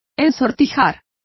Complete with pronunciation of the translation of curled.